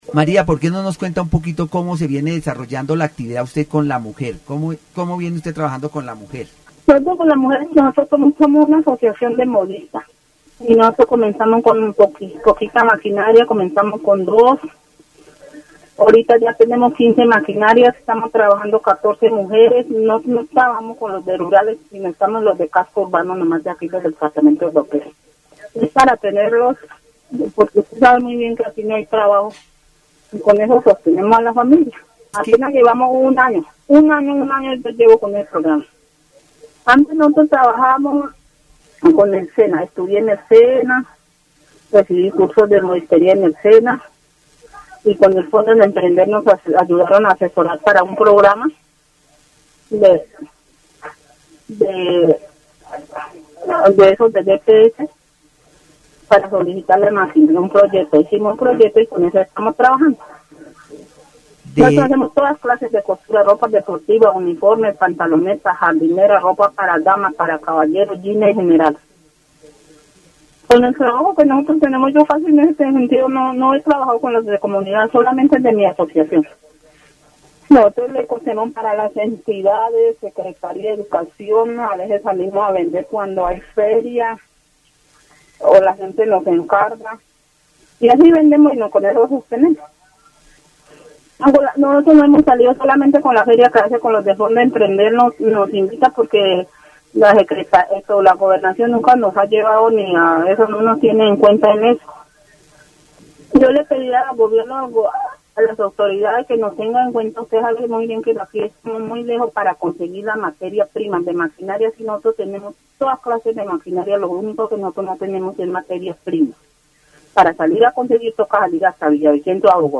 Vaupés (Región, Colombia) -- Grabaciones sonoras , Programas de radio , Voces y Regiones , Comunidad , Maquinaria , Modistas